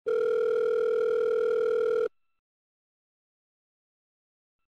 outgoing_call.mp3